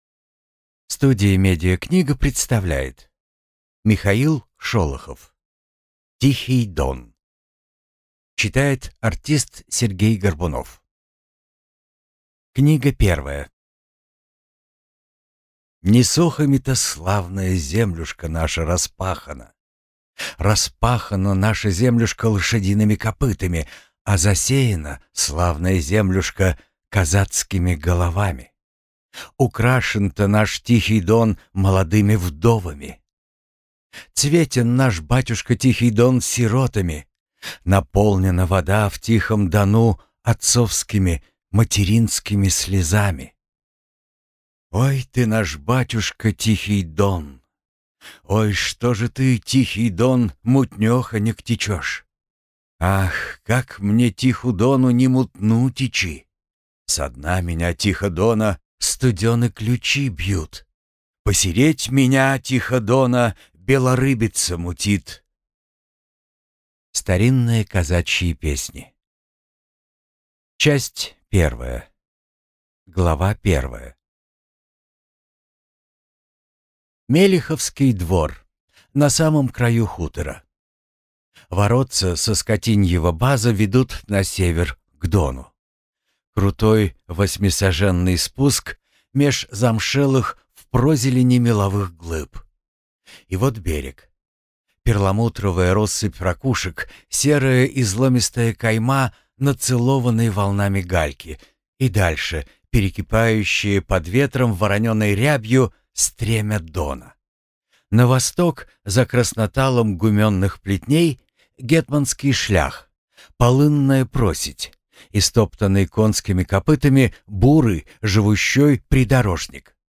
Аудиокнига Тихий Дон - купить, скачать и слушать онлайн | КнигоПоиск